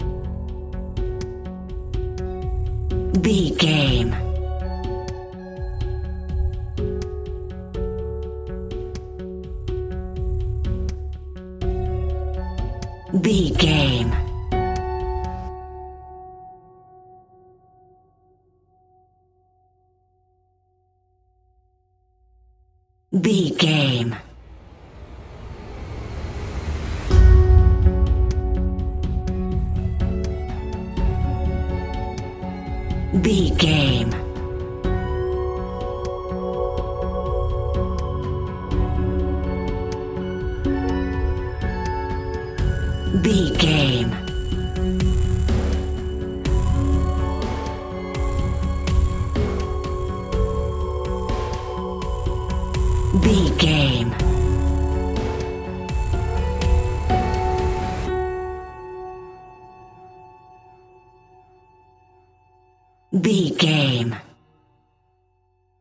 Ionian/Major
D♭
electronic
techno
trance
synths
synthwave
drone
glitch